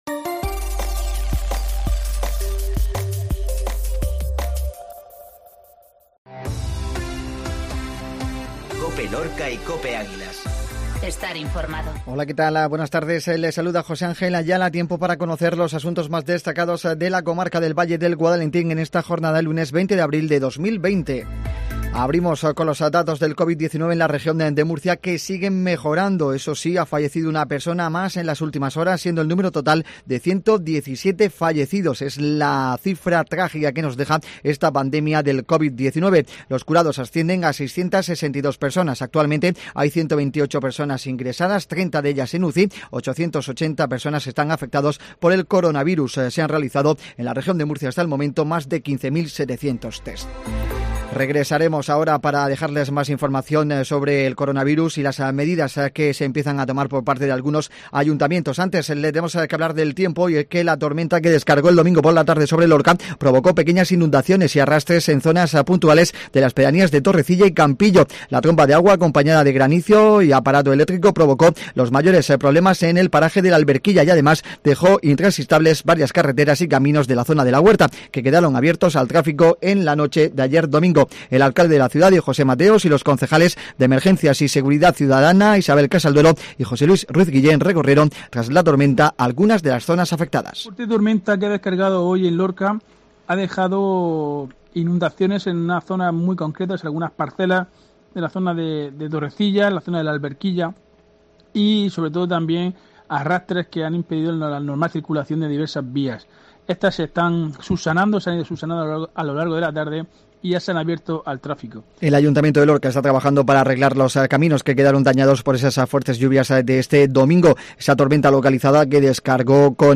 INFORMATIVO MEDIODIA COPE LORCA 2004